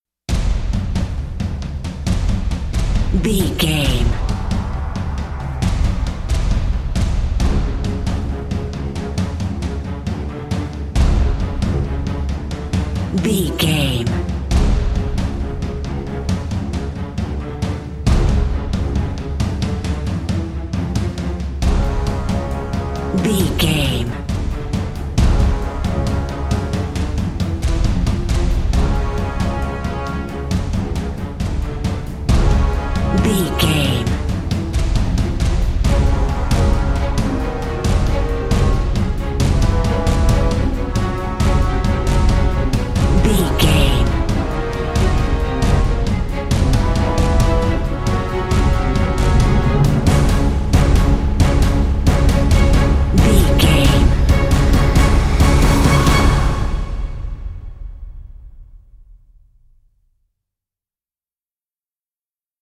Aeolian/Minor
C#
strings
percussion
cello
violin
orchestral
orchestral hybrid
dubstep
aggressive
energetic
intense
bass
synth effects
wobbles
driving drum beat
epic